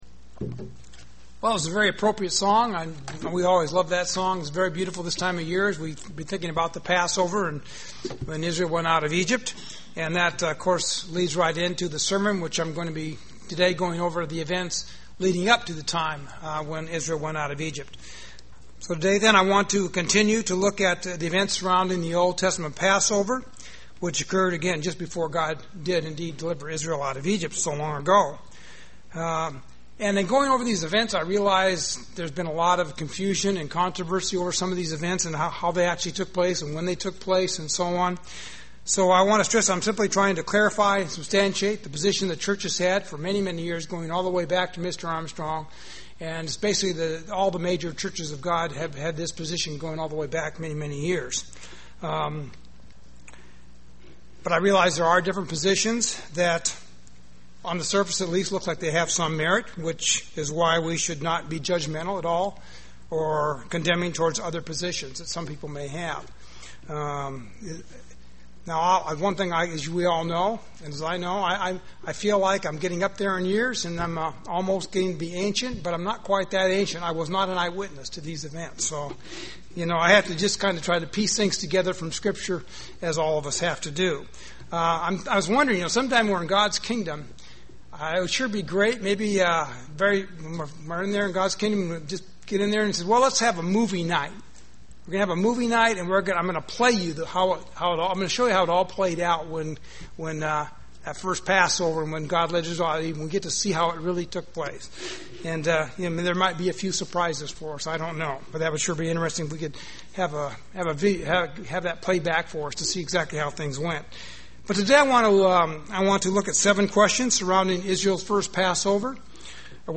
This sermon continues the series by going over the events leading up the time when Israel went out of Egypt by looking at the events surrounding the old testament passover.